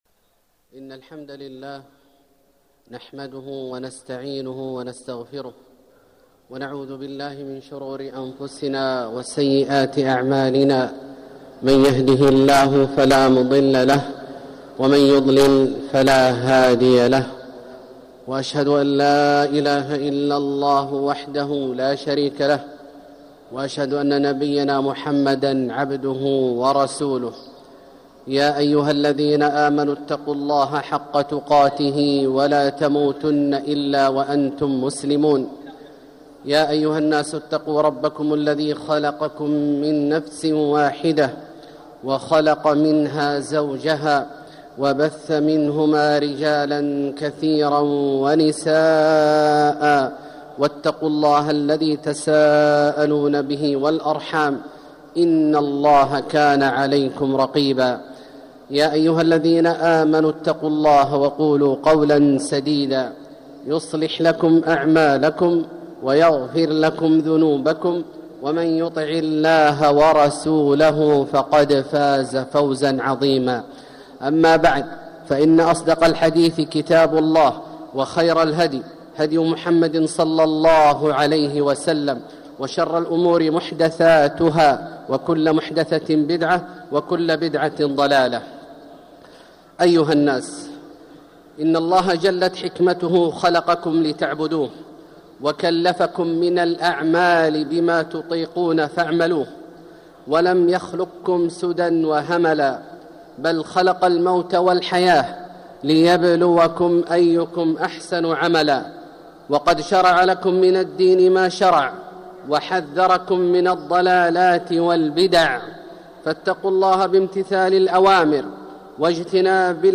مكة: التوبة من الذنوب - عبد الله بن عواد الجهني (صوت - جودة عالية. التصنيف: خطب الجمعة